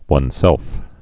(wŭn-sĕlf) also one's self (wŭn sĕlf, wŭnz sĕlf)